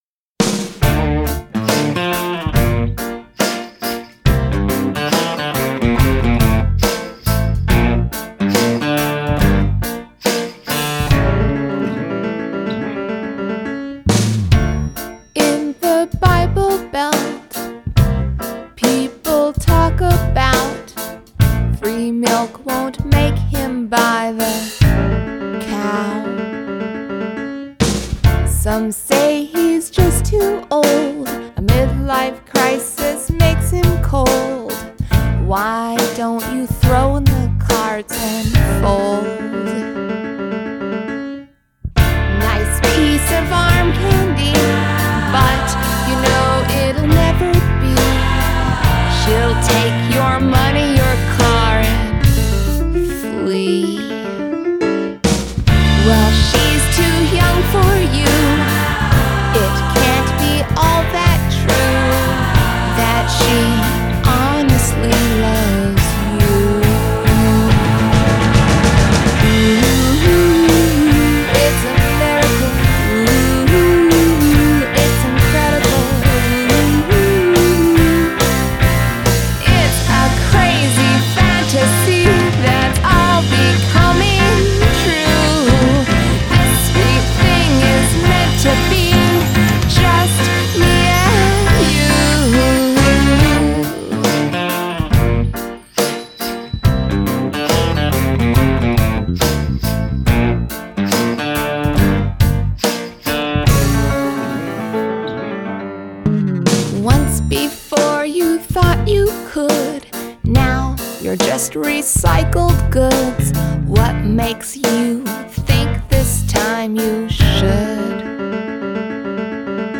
Adult Contemporary , Comedy
Indie Pop , Soft Rock